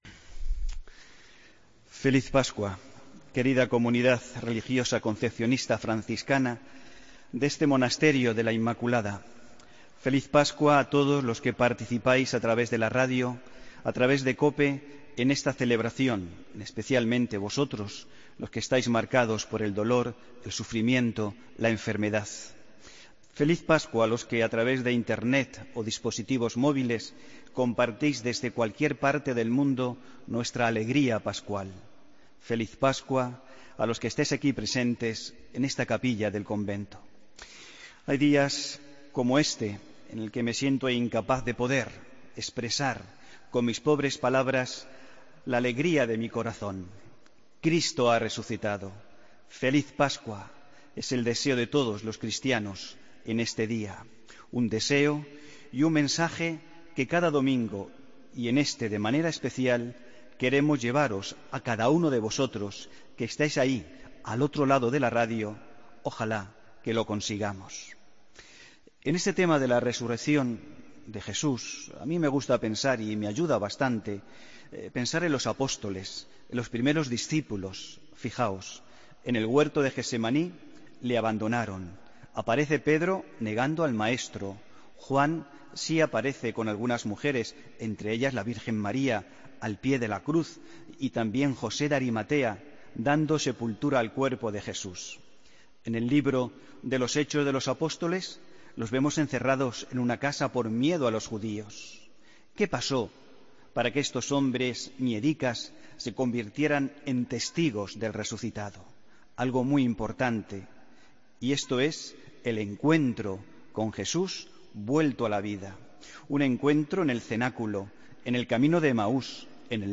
Homilía del domingo 27 de marzo de 2016